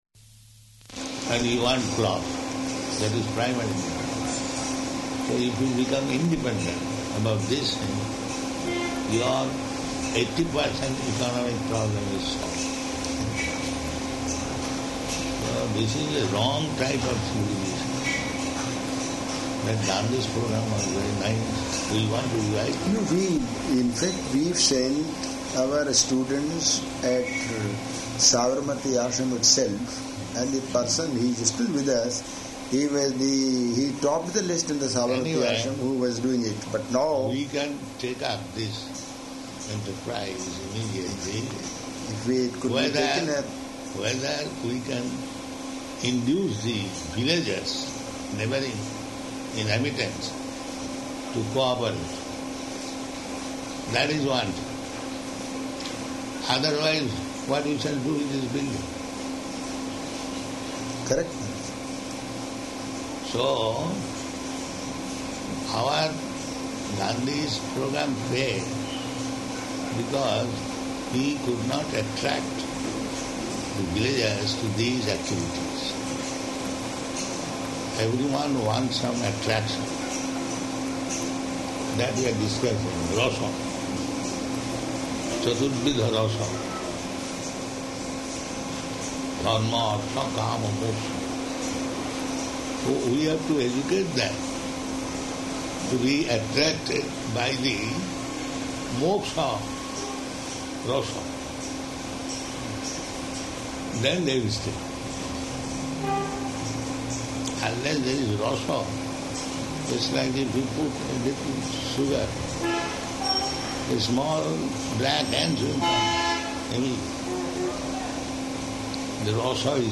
-- Type: Conversation Dated: April 23rd 1977 Location: Bombay Audio file